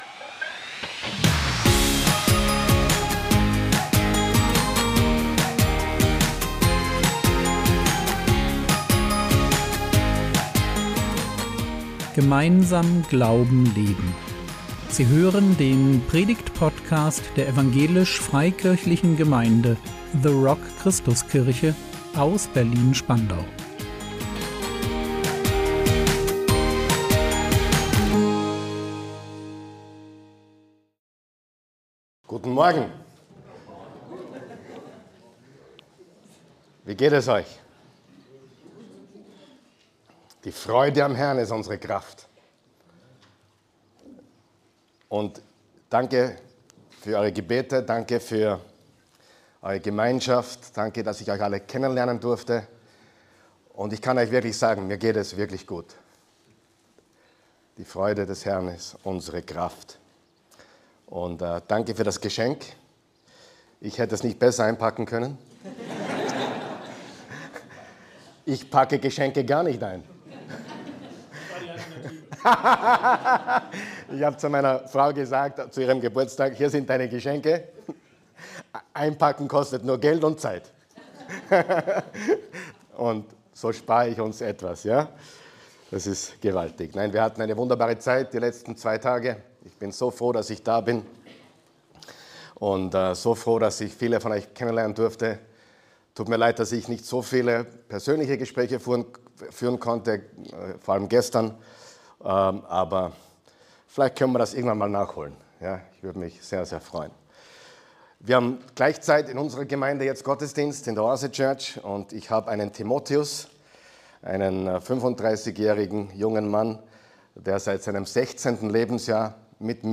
Gottes unfassbare Liebe | 28.04.2024 ~ Predigt Podcast der EFG The Rock Christuskirche Berlin Podcast